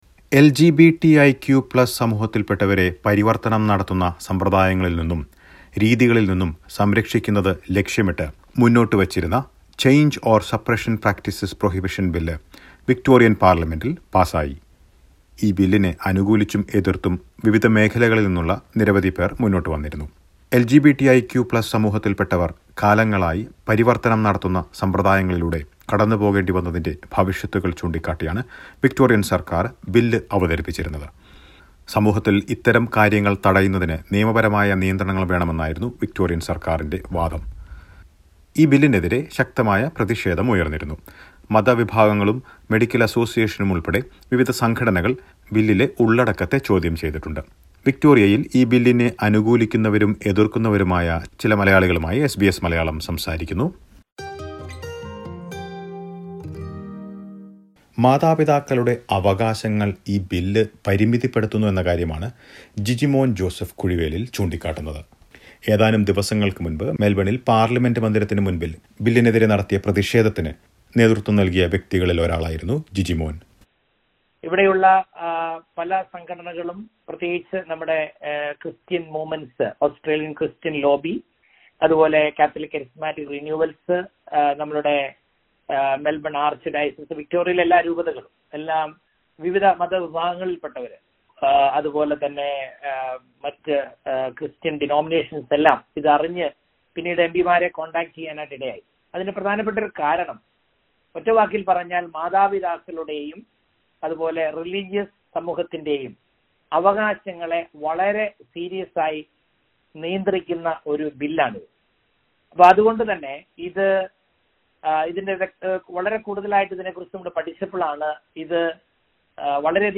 The Victorian parliament has voted to pass the Change or Suppression (Conversion) Practices Prohibition Bill after a marathon sitting in the parliament overnight. While many supported the bill, there was strong opposition from many. Listen to a report.